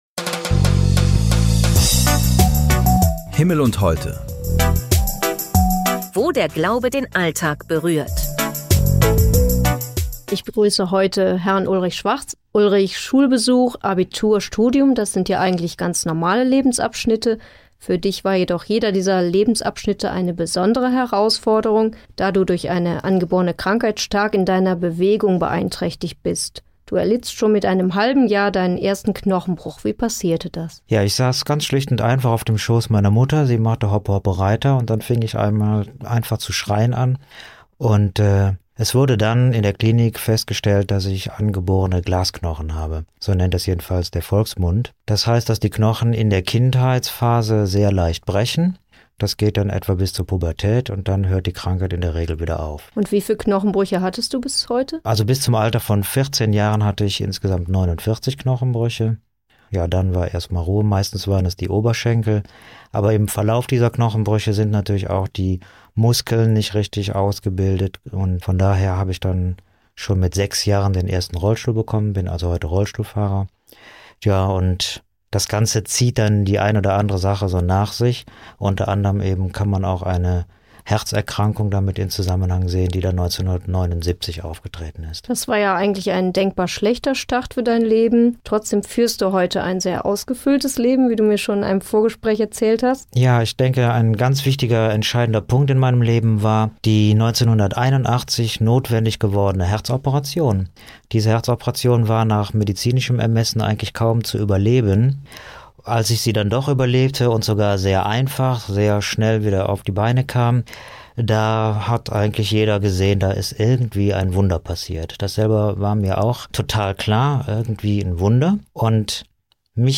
Ein motivierendes Lebenszeugnis...